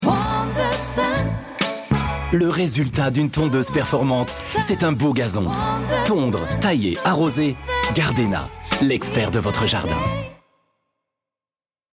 Meine Stimme (exakt, klar, verbindlich, flexibel, sympatisch) eignet sich optimal für Werbe- oder Dokufilme bzw. für Lernmethoden.
Opernsänger Schauspieler französische Off-Stimme Dokufilme Werbefilme Lernmethoden, Synchro.
Sprechprobe: Industrie (Muttersprache):
Sympathic and warm french voice for your corporate moovies, e-learning, audiobooks, spots etc...